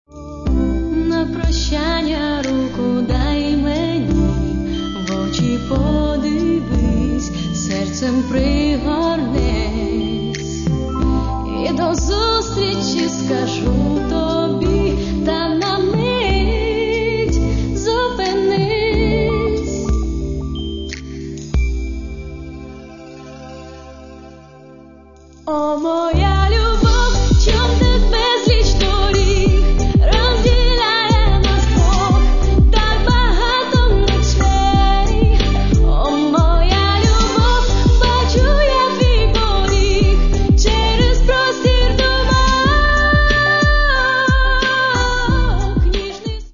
Каталог -> Поп (Легка) -> Поп російською